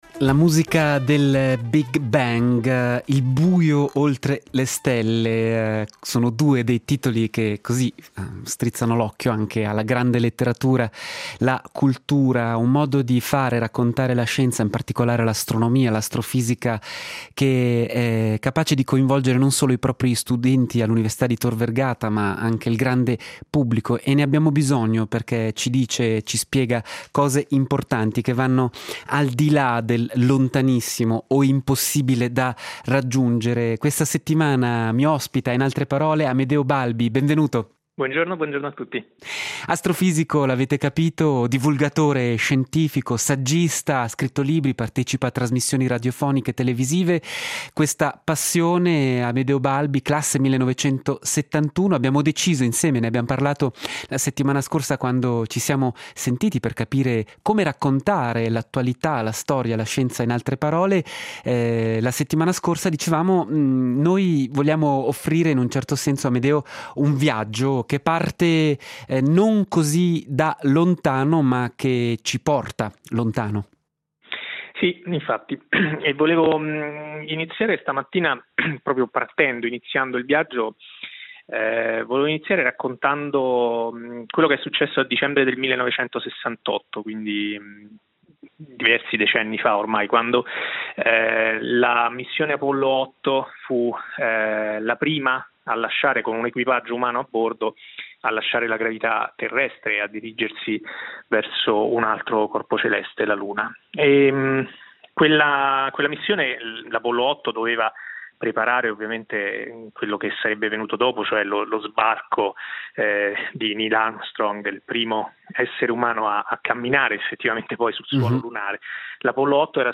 “In altre parole” ha pensato di chiedere ad un astrofisico e divulgatore scientifico di accompagnare gli ascoltatori nella consueta rassegna di conversazioni del mattino.